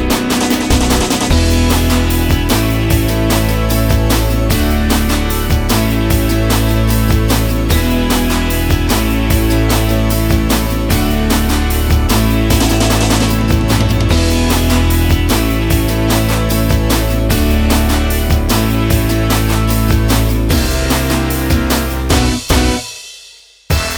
No Backing Vocals Soundtracks 4:10 Buy £1.50